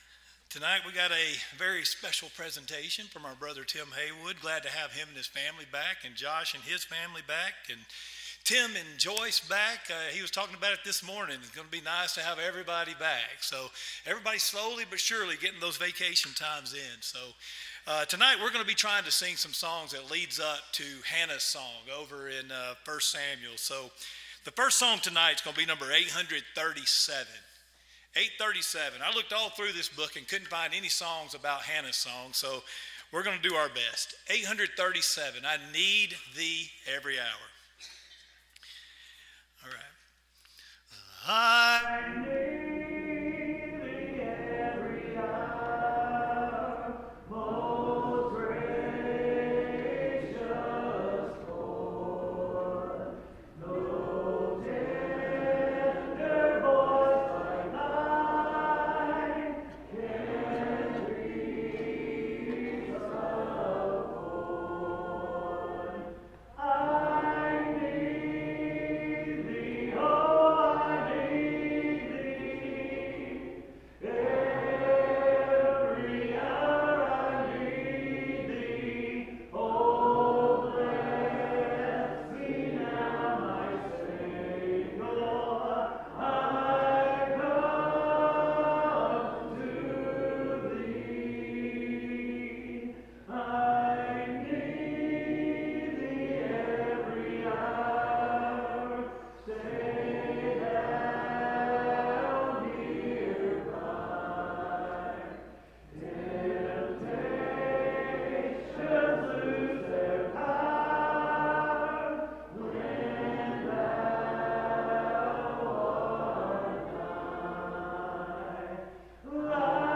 Series: Sunday PM Service